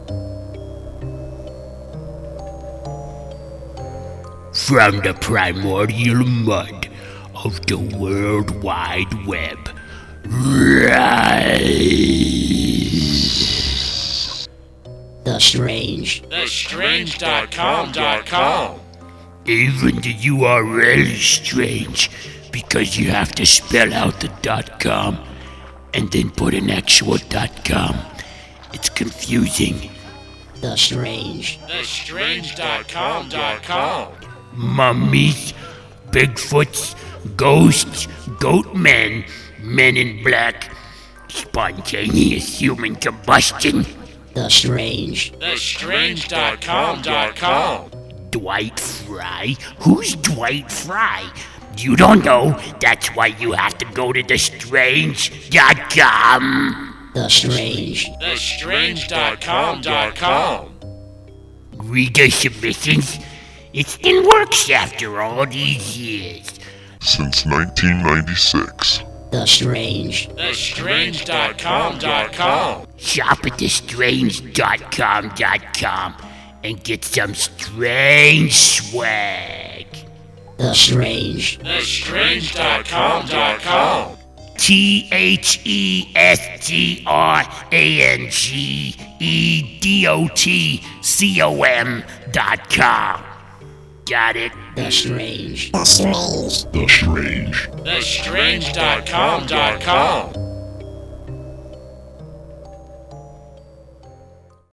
We also have an audio promo.